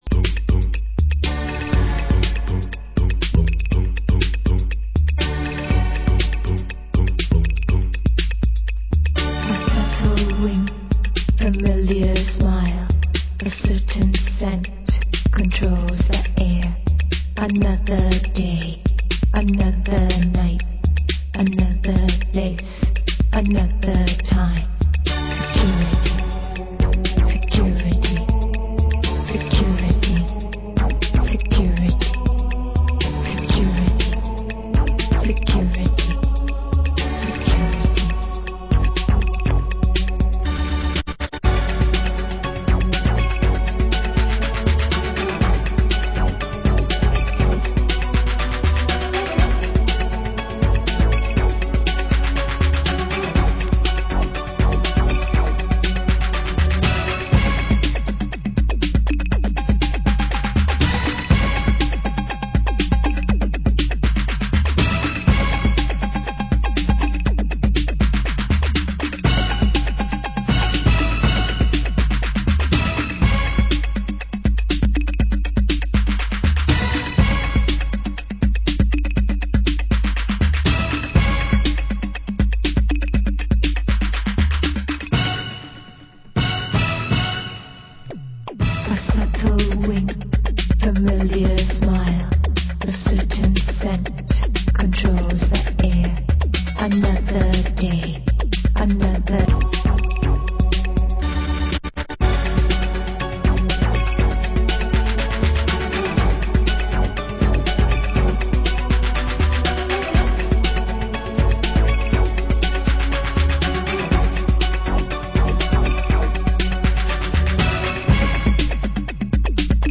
Genre: Old Skool Electro